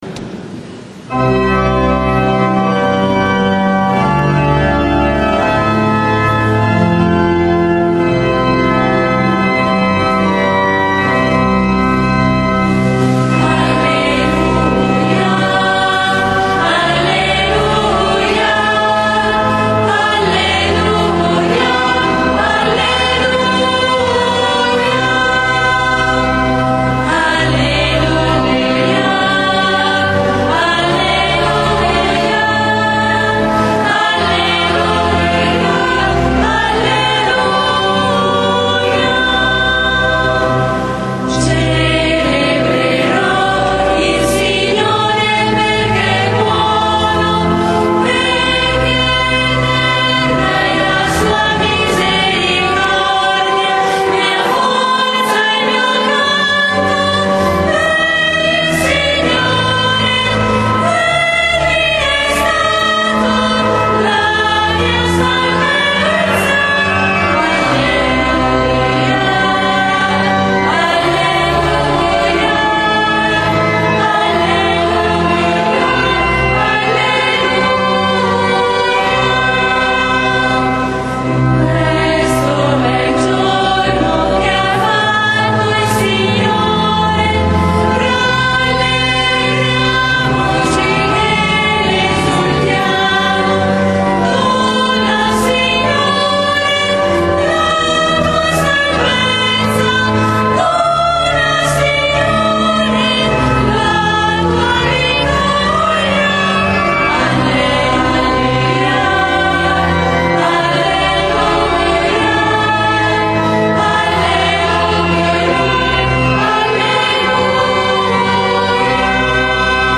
SABATO SANTO -Celebrazione della Resurrezione del Signore
canti: Cantico dell'Agnello - Sono risorto